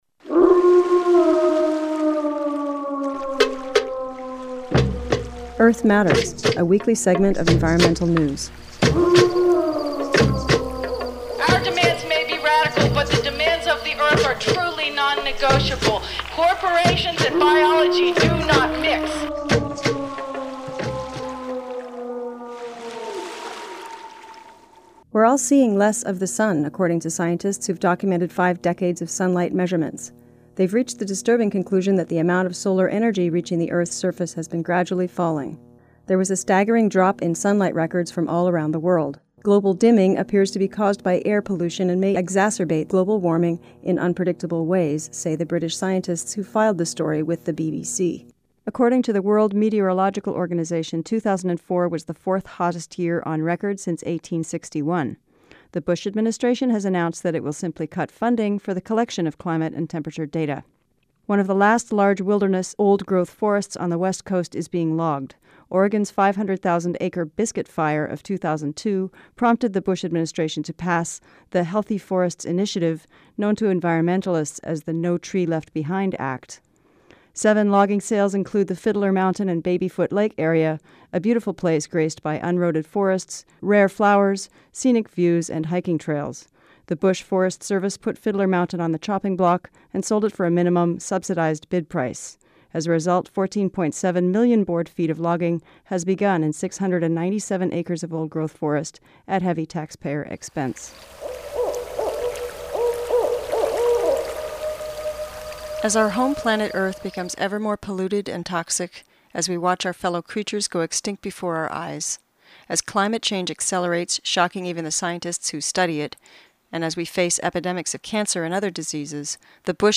An interview with Dr. Helen Caldicott from her home in Australia.